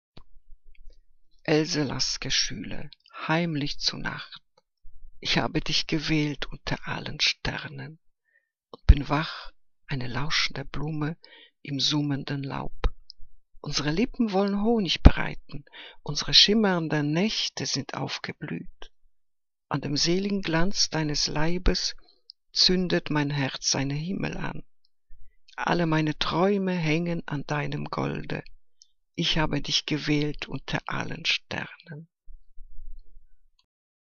Liebeslyrik deutscher Dichter und Dichterinnen - gesprochen (Else Lasker-Schüler)